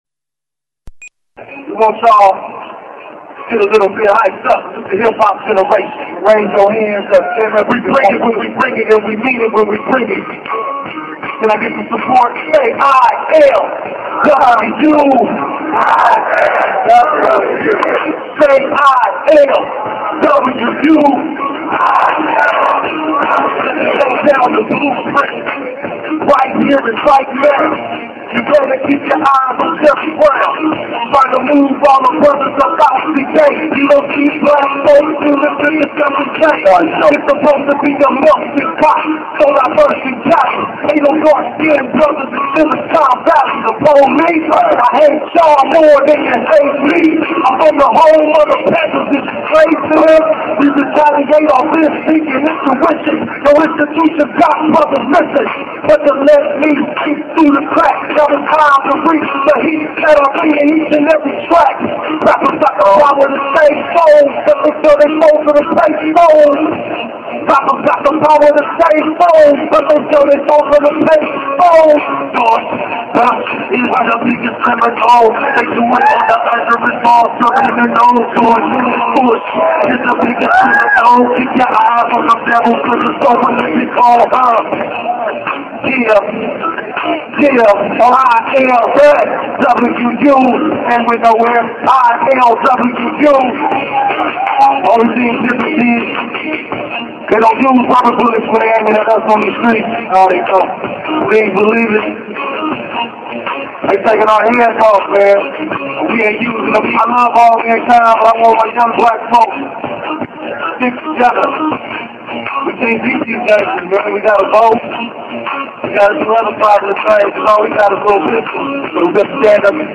§Some rap songs from the rally
here are some rap songs from the rally, if someone knows the group's name, let me know- they had some cool lyrics.
rap_songs_from_oakland_city_hall_rally.mp3